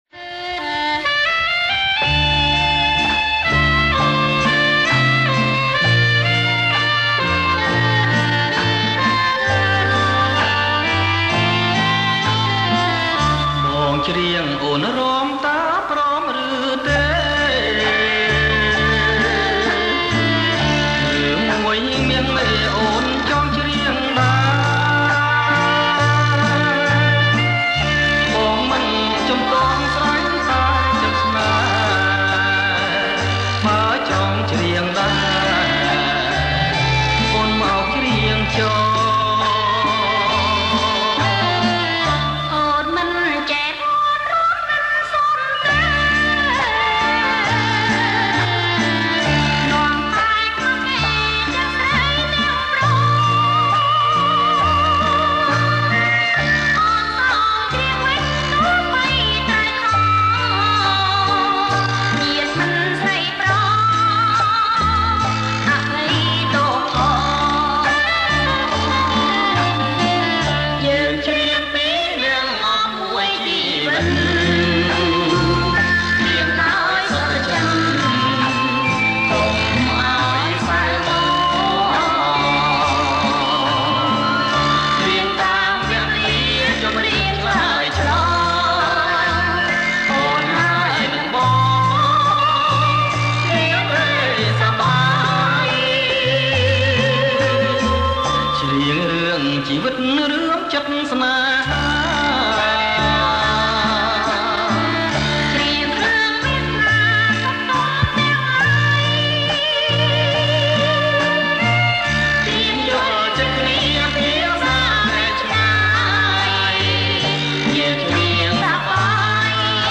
ប្រគំជាចង្វាក់ Bolero Lent